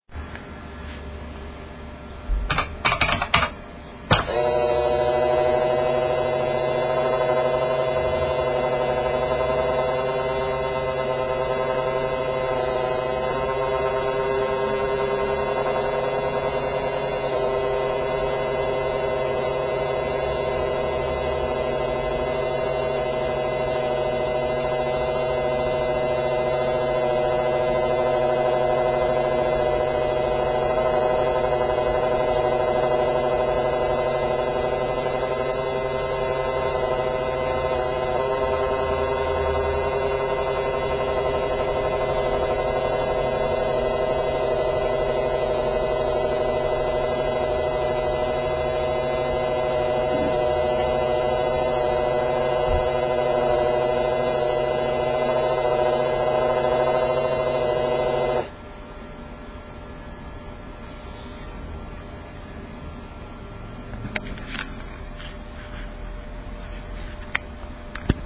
stringy floppy sound capture
The stringy floppy makes a characteristic whirr (rising and falling slightly) as the tape in the wafer gets dragged around in its endless loop.
I took the simple approach and just sat a digital voice recorder next to the drive as it loaded a wafer (see photo opposite).
stringy-floppy-sound.WMA